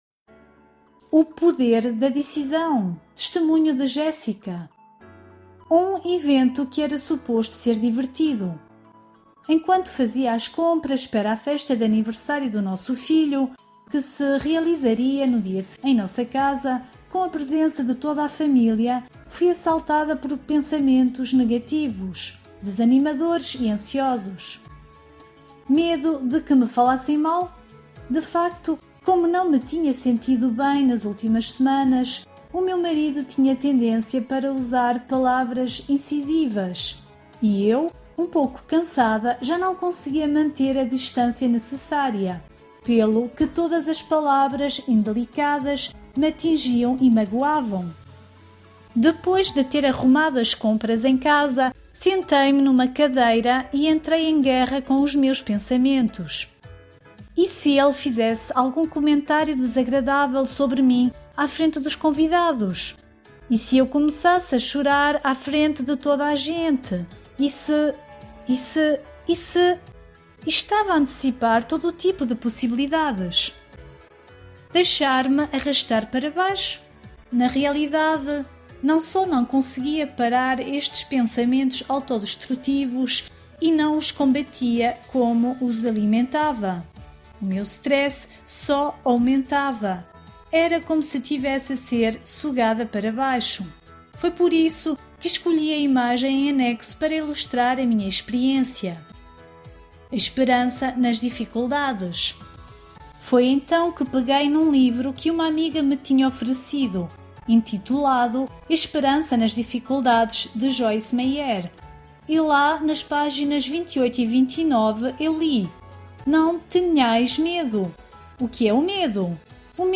audio com música
O-poder-da-decisao_music.mp3